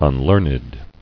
[un·learn·ed]